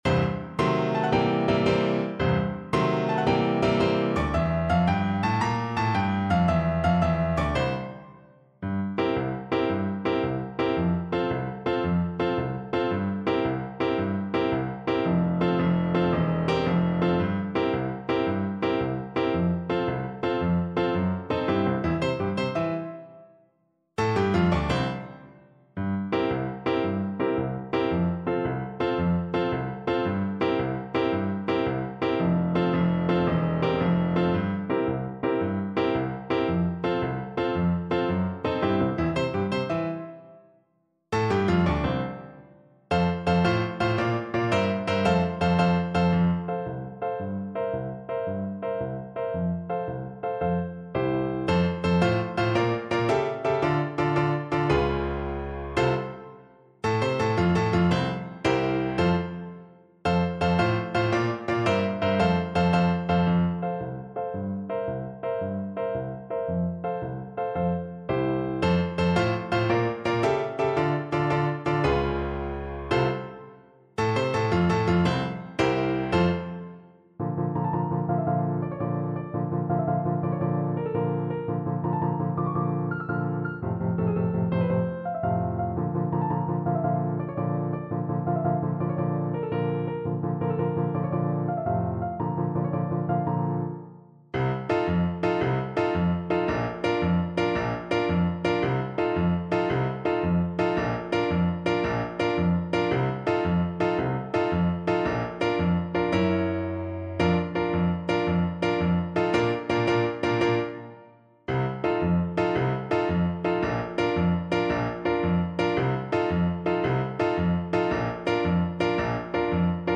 6/8 (View more 6/8 Music)
F4-C6
Quick March (. = c.112)
Classical (View more Classical Tenor Saxophone Music)